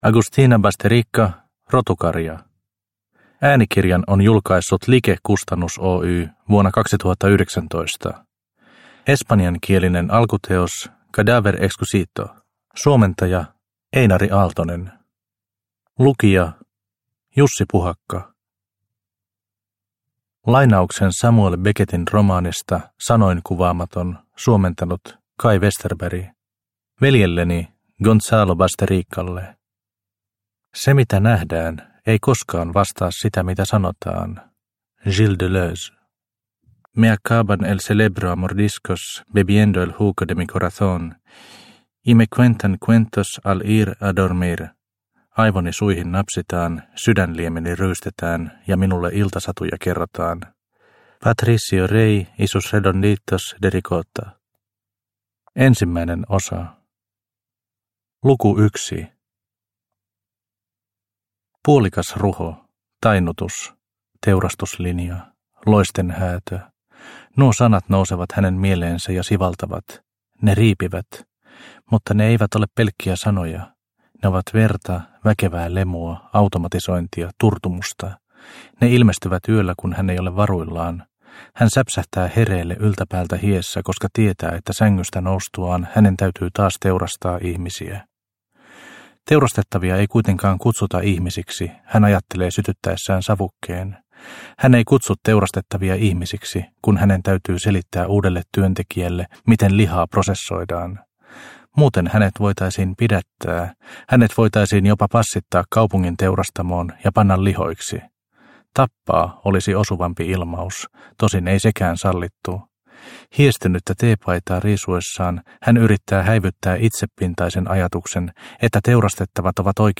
Rotukarja – Ljudbok – Laddas ner